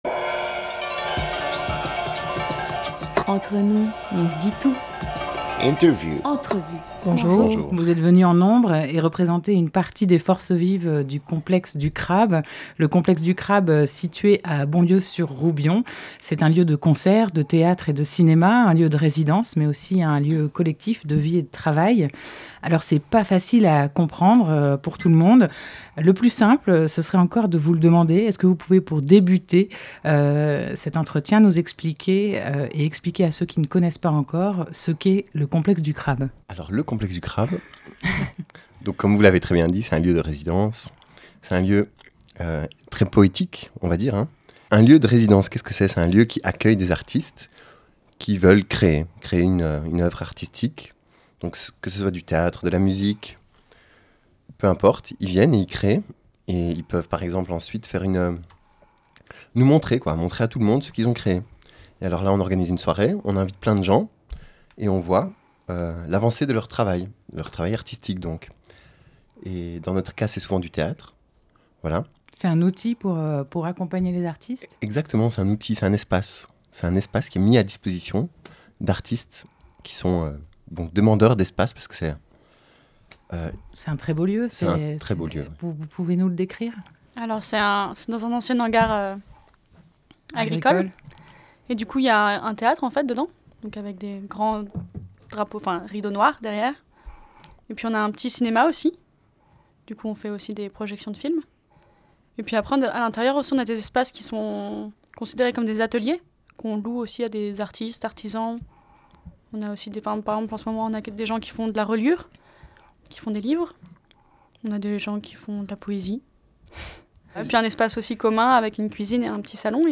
30 octobre 2017 16:12 | Interview
Une veille de Toussaint, un bout de l’équipe du Complexe du Crabe est passé au studio de RadioLà, histoire de vous raconter ce lieu à la croisée des chemins, dans lequel il fait bon rencontrer comédiens, poètes et musiciens….
ITW-Complexe-du-crabe.mp3